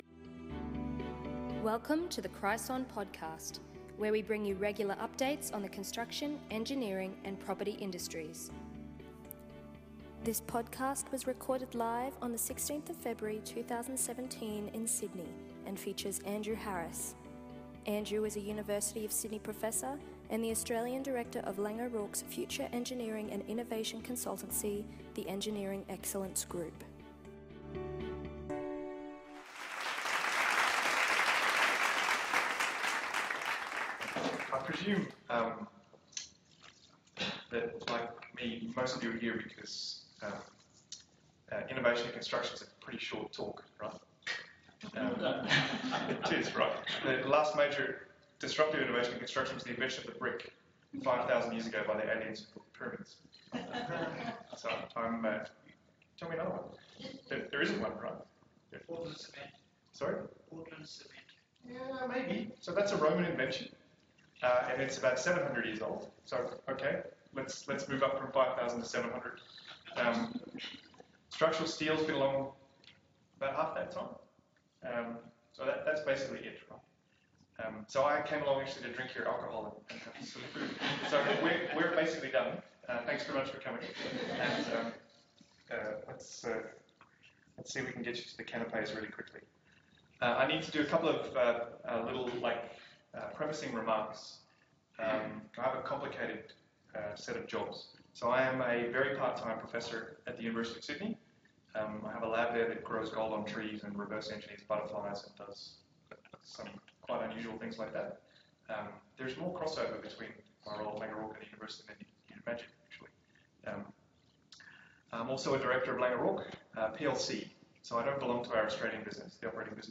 Following a successful programme of industry seminars in 2016, Kreisson launched the 2017 Seminar Programme with on 16 February 2017.